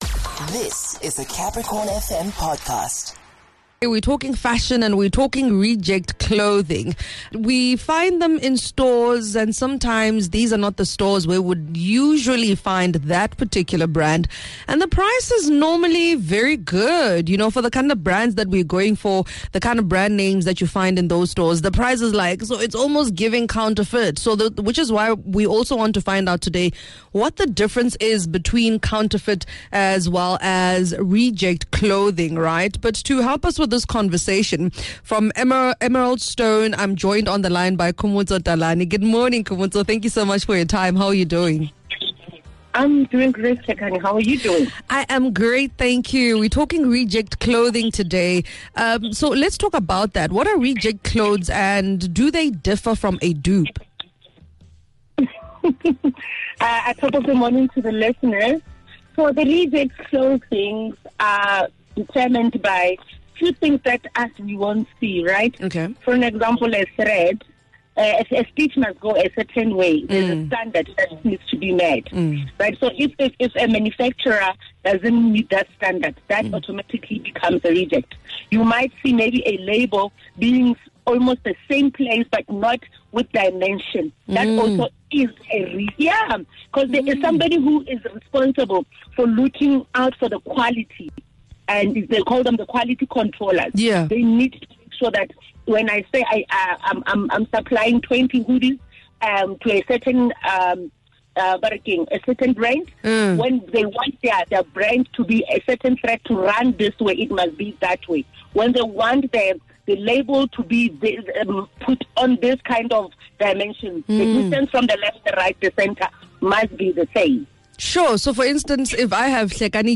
had a conversation